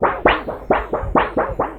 ALETEO_WAV.wav